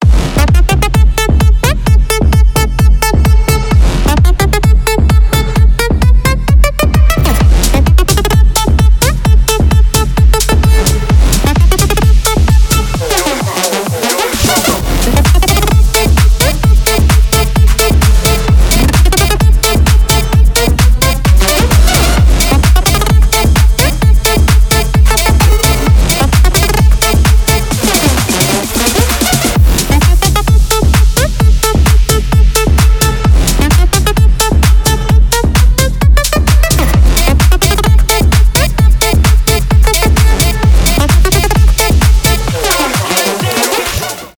клубные , dutch house , electro house